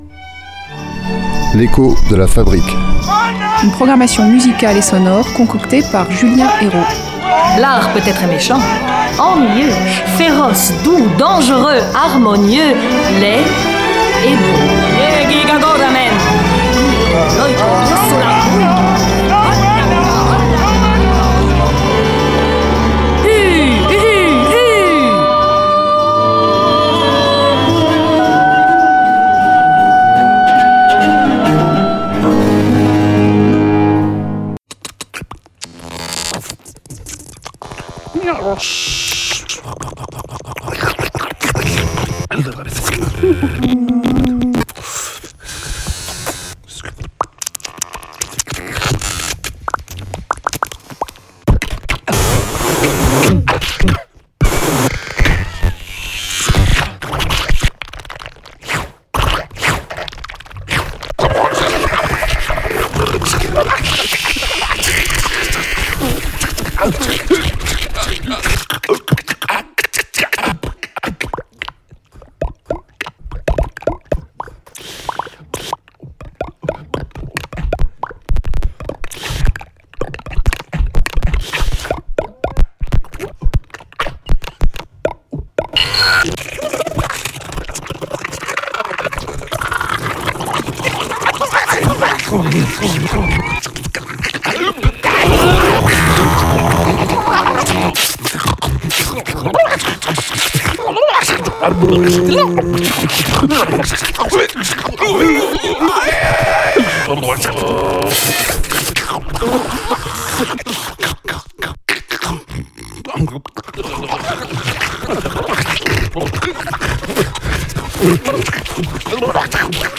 voix, synthétiseur, piano, objets, électronique
ordinateur, synthétiseur, électronique, objets